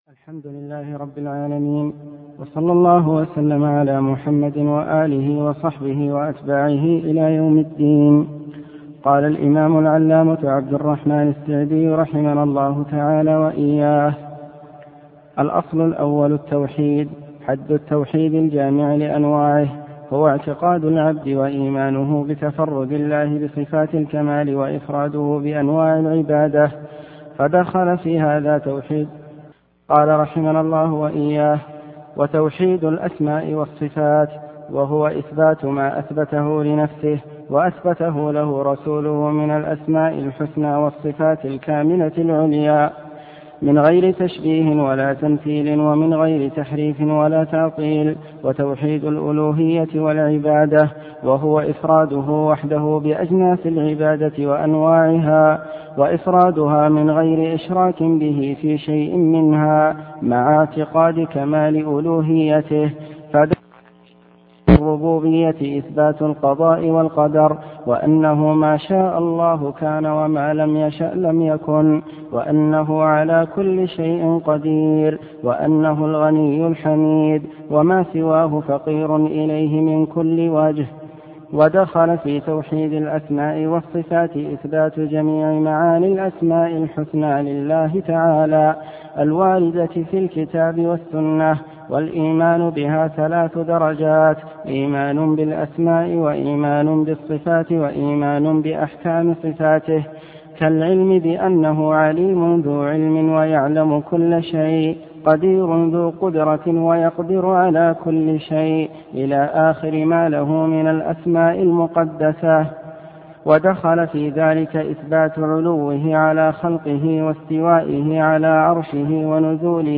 عنوان المادة الدرس (2) شرح مختصر في أصول اعتقاد أهل السنة تاريخ التحميل السبت 31 ديسمبر 2022 مـ حجم المادة 21.21 ميجا بايت عدد الزيارات 192 زيارة عدد مرات الحفظ 103 مرة إستماع المادة حفظ المادة اضف تعليقك أرسل لصديق